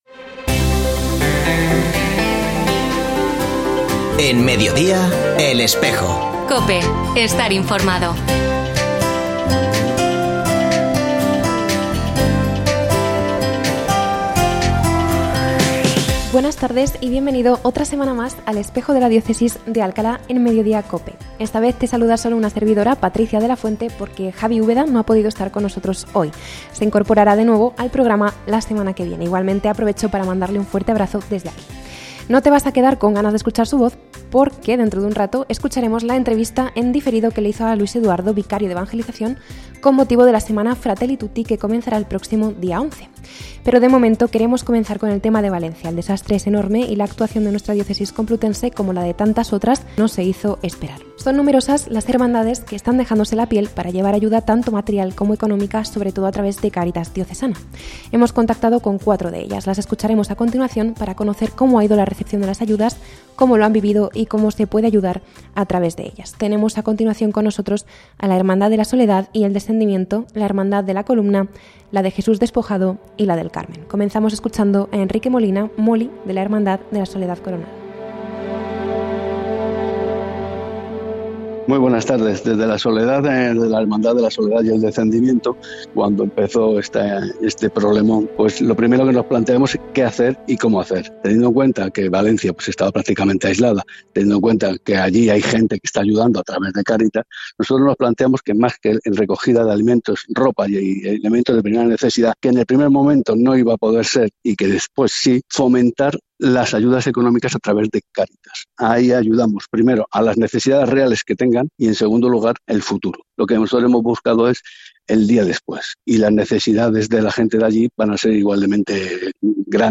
Escucha otras entrevistas de El Espejo de la Diócesis de Alcalá
Se ha vuelto a emitir hoy, 8 de noviembre de 2024, en radio COPE.